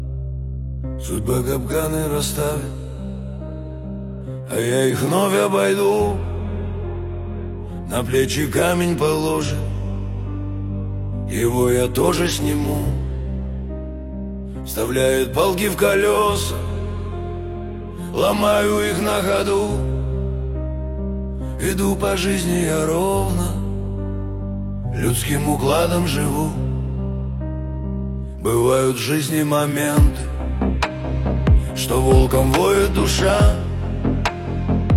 Жанр: Русские
Chanson in Russian